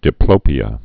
(dĭ-plōpē-ə)